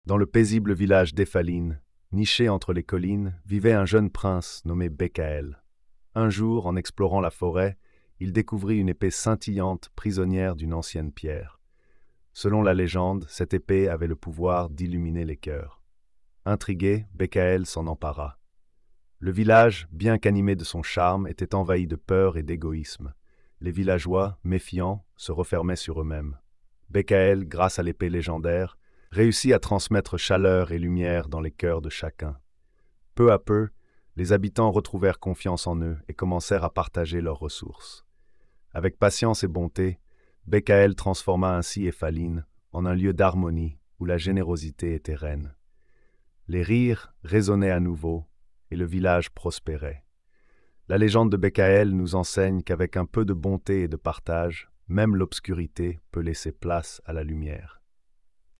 Conte de fée
🎧 Lecture audio générée par IA